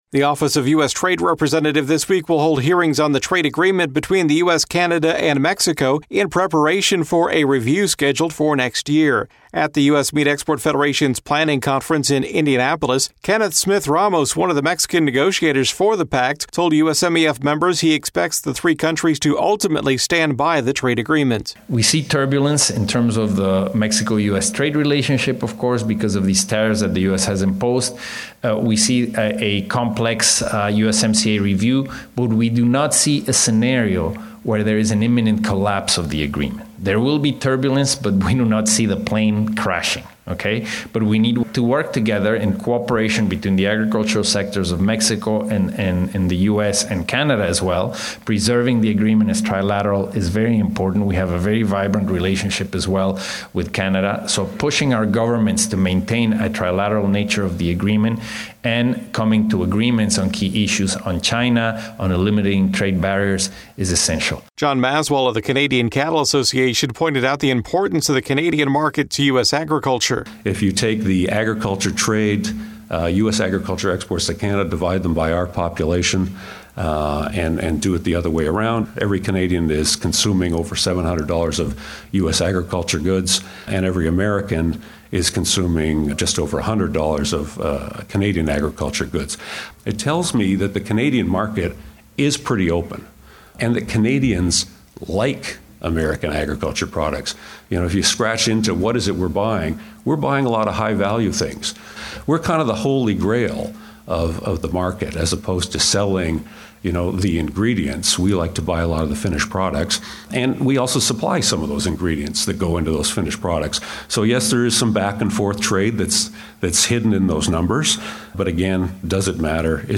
A keynote session of the U.S. Meat Export Federation (USMEF) Strategic Planning Conference in Indianapolis took a close look at the agreement and its impact on red meat trade, with panelists offering insights on the benefits of USMCA and what to expect from the upcoming review.